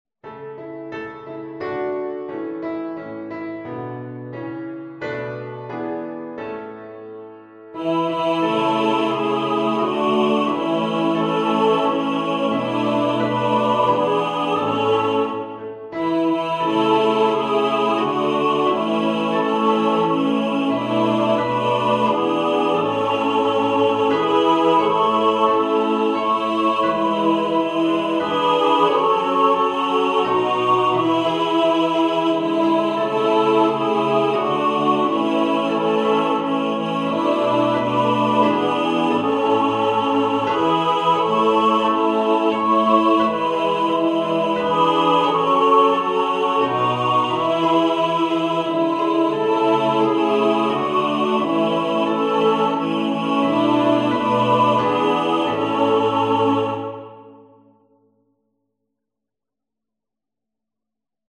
ist ein melodisches Weihnachtslied